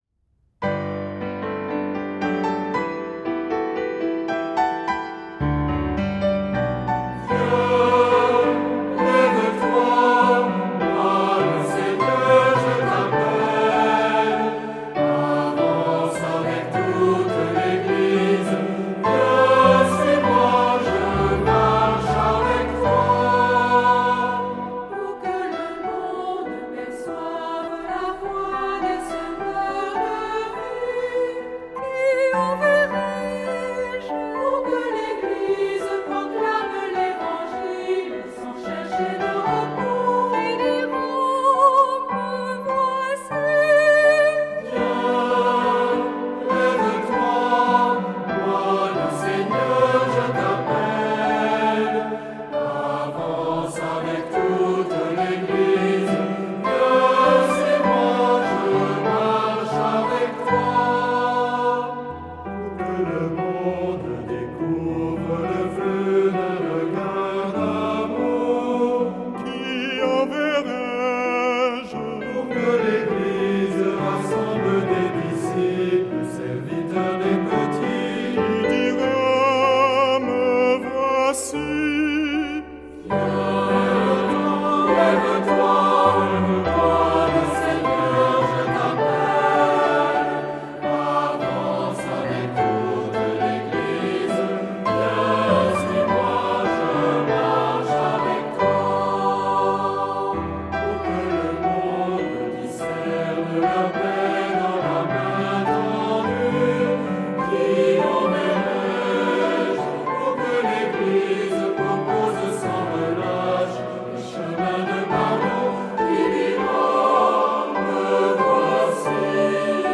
Versets par un soliste ou une petite schola.